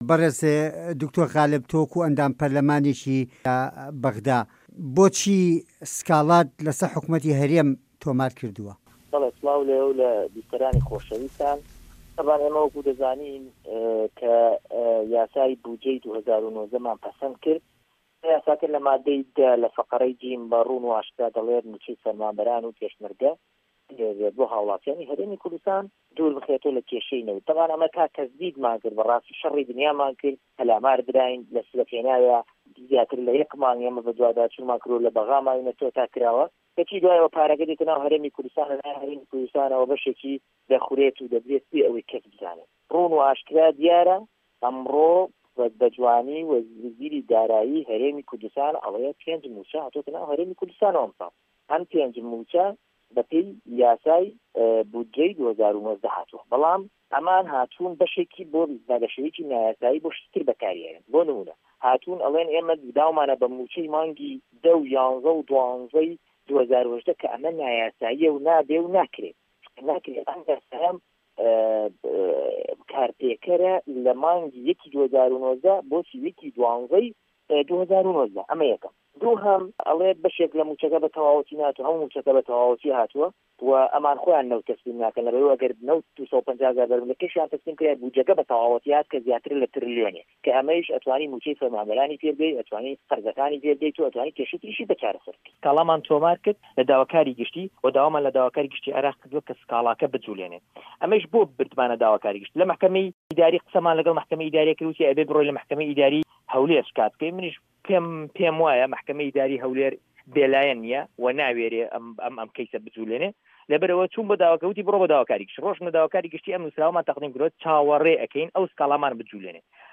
وتووێژ لەگەڵ دکتۆر غالب محەمەد